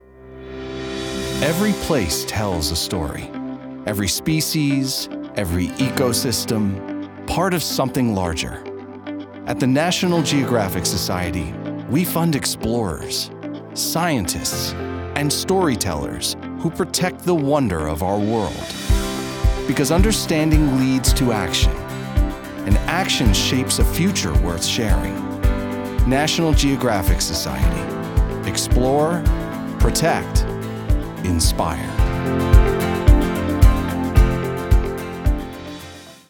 Reflective · Inspiring · Intelligent
Cinematic, contemplative narration for documentaries, nature content, and prestige storytelling.